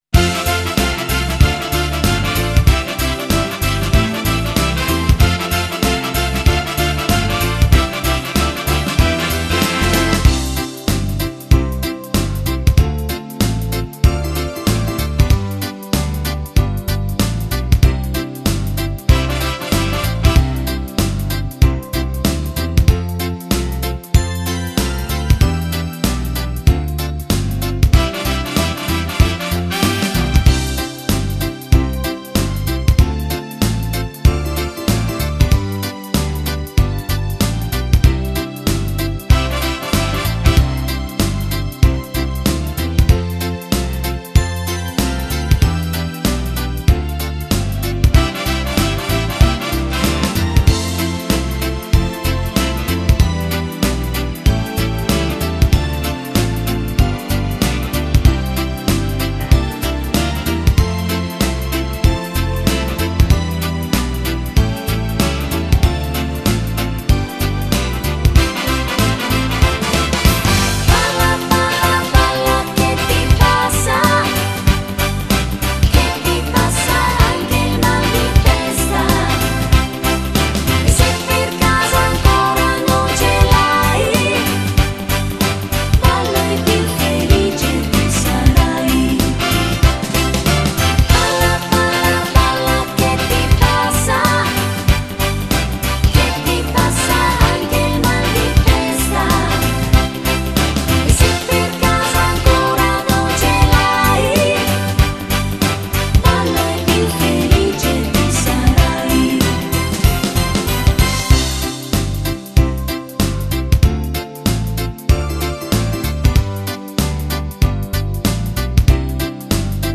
Genere: Moderato fox